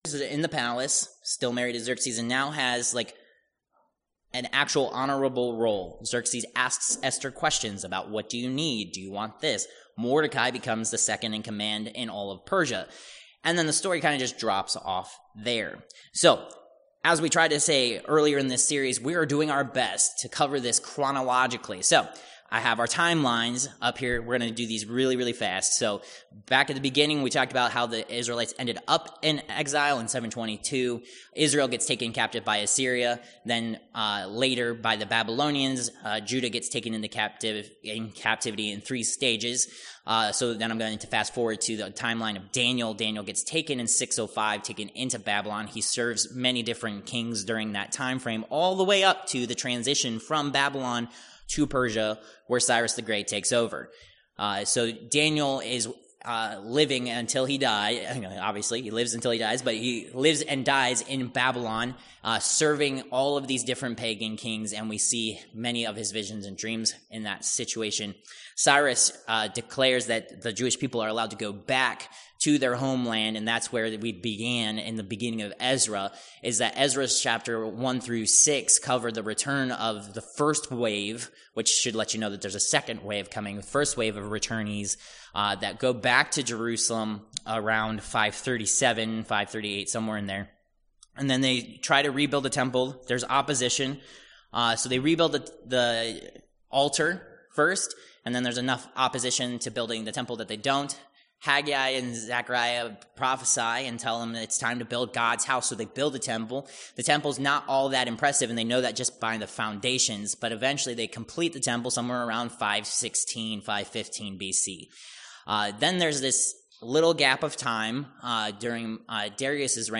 Ezra 7-8 Service Type: Worship Service « Protected